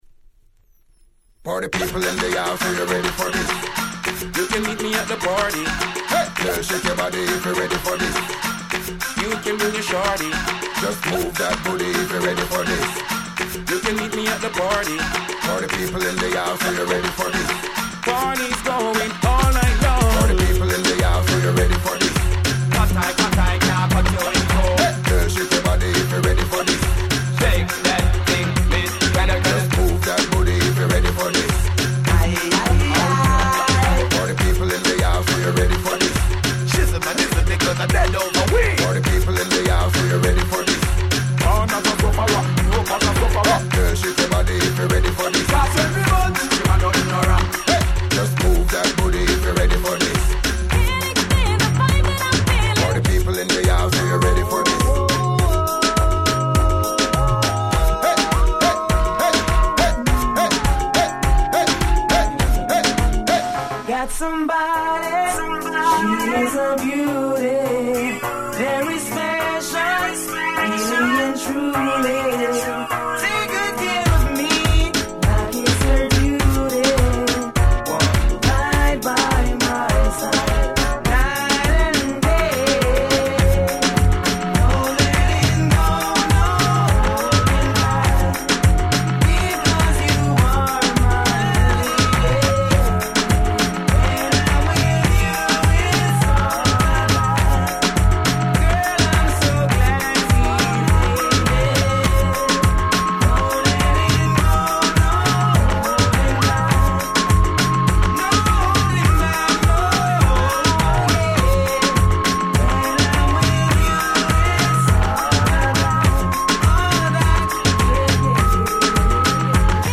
03' Super Party Tracks !!
Mega Mix)